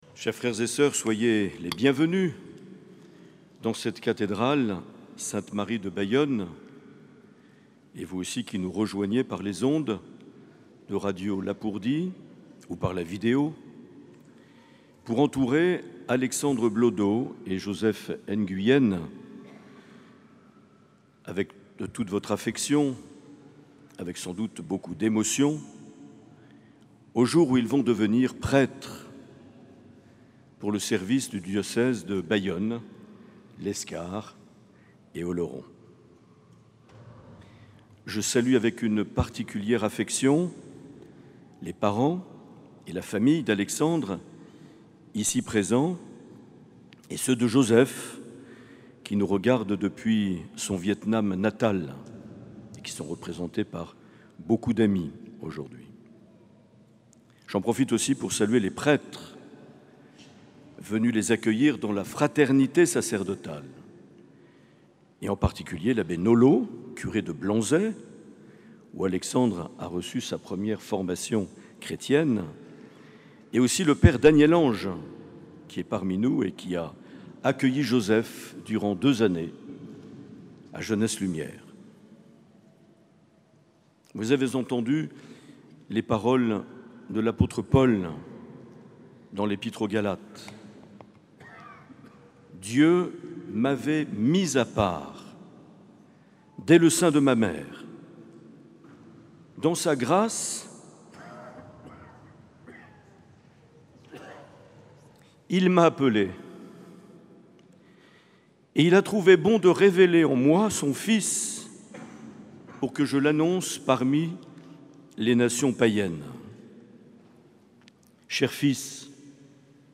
Homélie de Mgr Marc Aillet.